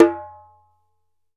ASHIKO 4 0AL.wav